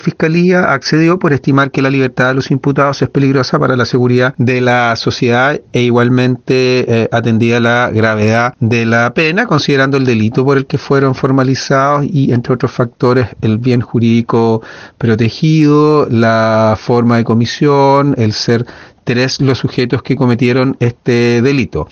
Así lo señaló el Fiscal adjunto de Ancud, Fernando Metzner.
fernando-metzner-fiscal-ancud2.mp3